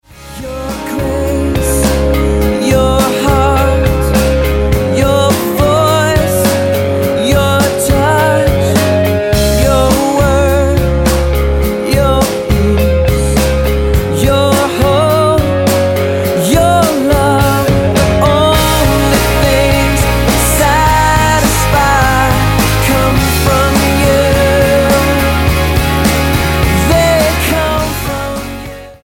STYLE: Rock
surging bursts of rock guitar